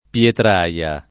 pLetr#La] s. f. — oggi lett. petraia [petr#La]: Col livido color de la petraia [kol l&vido kol1r de lla petr#La] (Dante) — la Petraia (non la Pietraia), villa medicea presso Firenze — la Petraia anche altri luoghi meno conosciuti (Tosc., Lazio); Pietraia, invece, due centri abitati presso Cortona (Tosc.) e presso Perugia (Umbria)